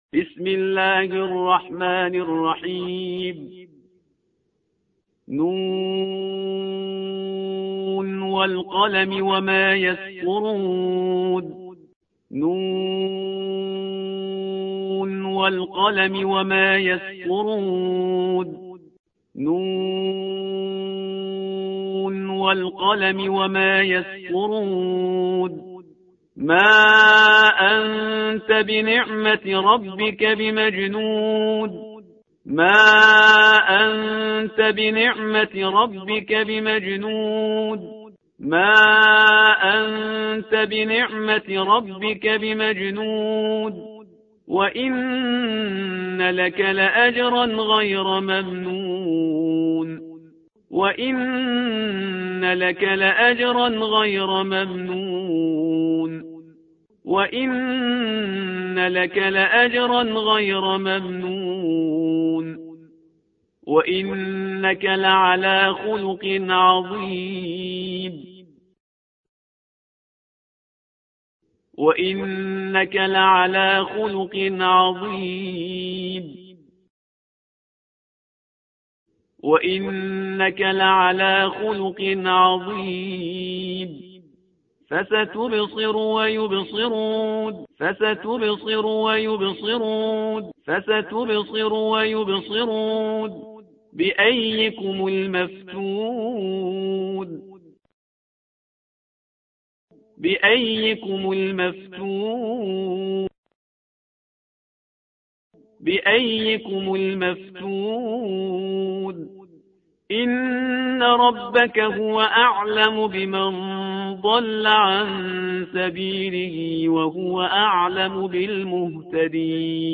فایل های صوتی سه بار تکرار از استاد پرهیزگار- سوره قلم